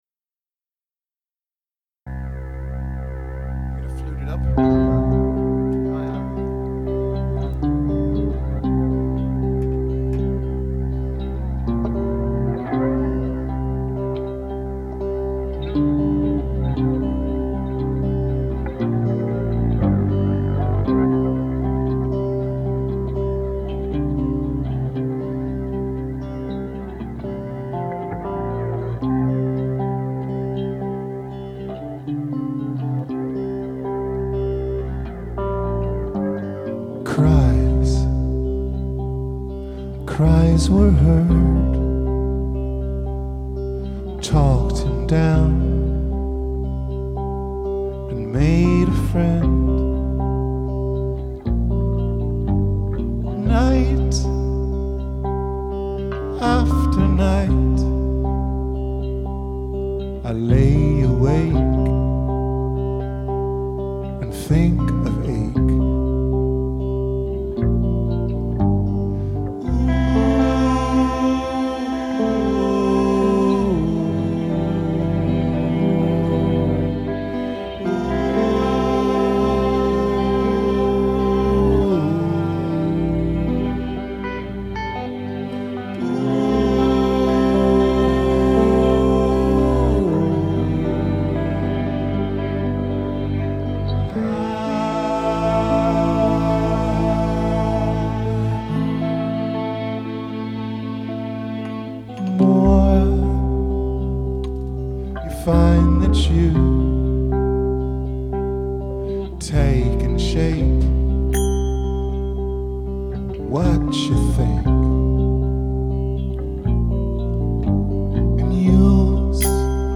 Another funny recording at 6.1.2012 rehearsals